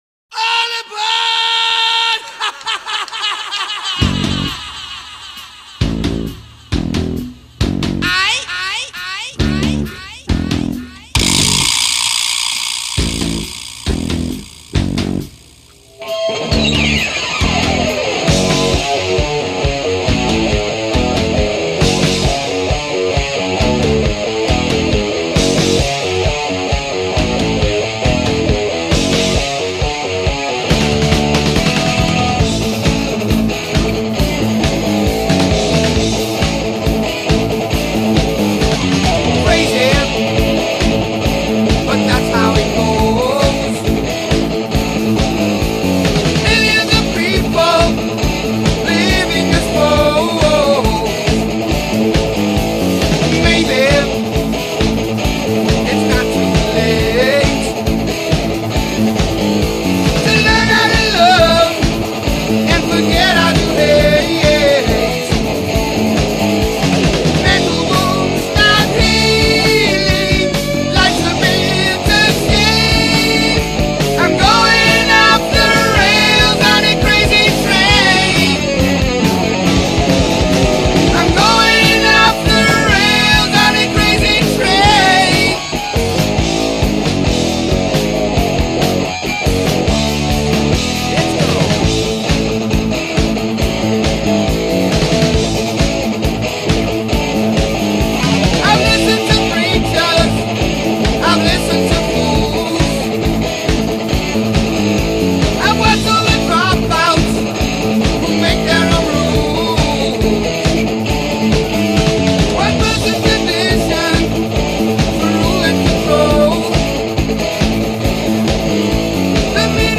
Heavy Metal, Hard Rock